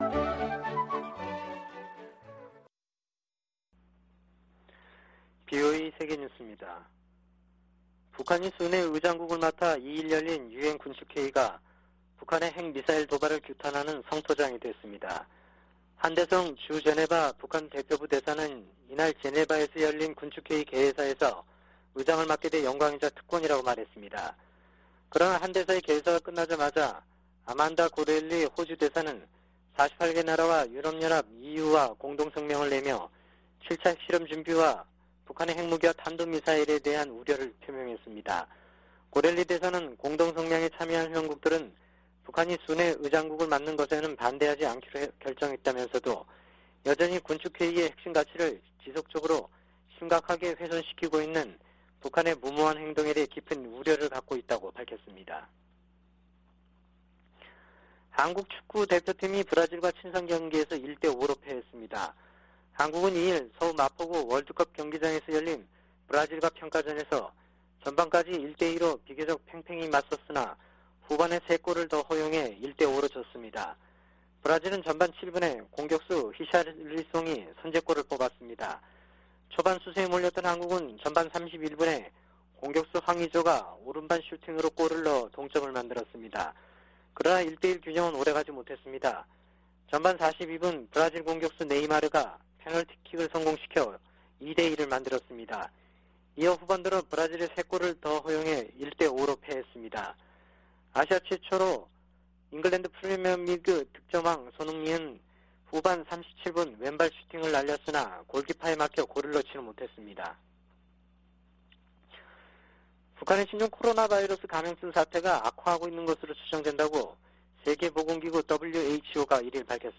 VOA 한국어 아침 뉴스 프로그램 '워싱턴 뉴스 광장' 2022년 6월 3일 방송입니다. 북한의 7차 핵실험 준비 동향이 잇따라 포착되고 있는 가운데 3일 미·한·일 북 핵 수석대표들이 서울에 모여 대책을 논의합니다. 토니 블링컨 미 국무장관은 중국이 국제 현안들을 해결하는데 중요한 역할을 해야 한다며 그 중 하나로 북한 핵 문제를 꼽았습니다. 주한미군사령관과 일본 자위대 수장이 긴급 회동했습니다.